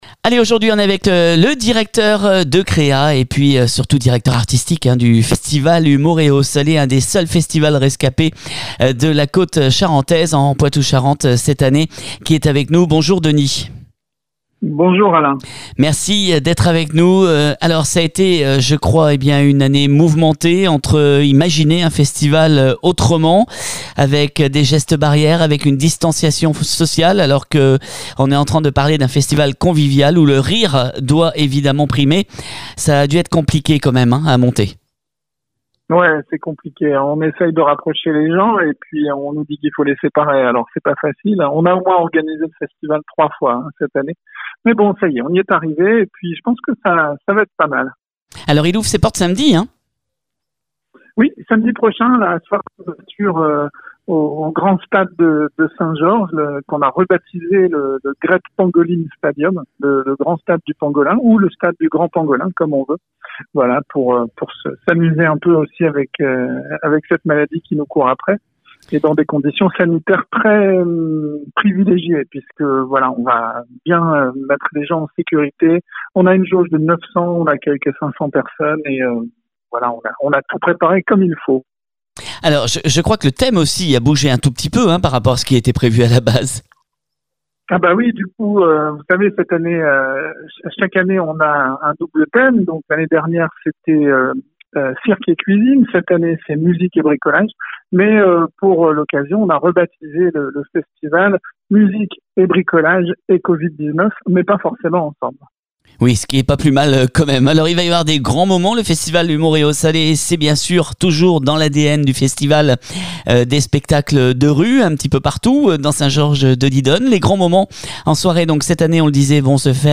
Voici une interview exclusive